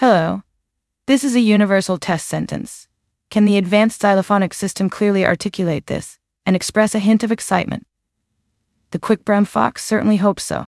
Open-Source-TTS-Gallary